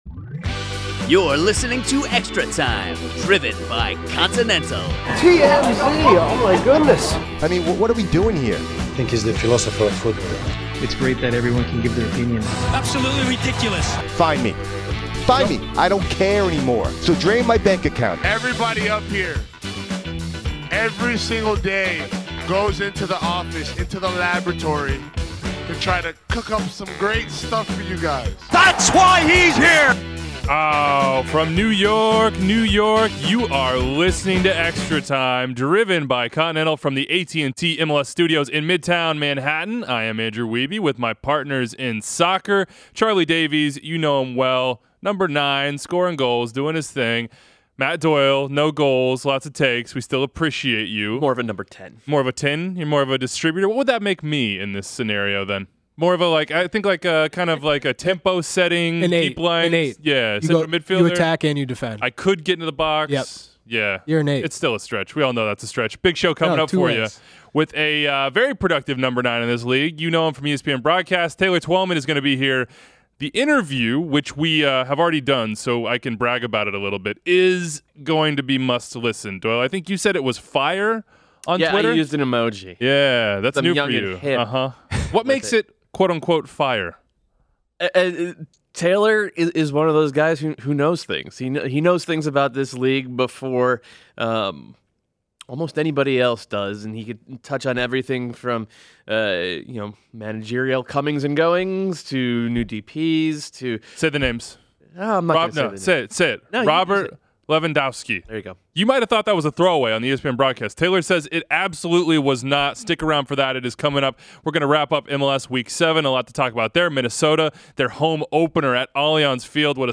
In the second segment, ESPN’s Taylor Twellman joins the guys to bounce around MLS. Topics covered: St. Louis vs. Sacramento for the 28th team, the Twellman family legacy at Allianz Field, NYCFC’s winless campaign so far, CCL failure and solutions, Robert Lewandowski to MLS (!!!) and Jesse Marsch to Salzburg.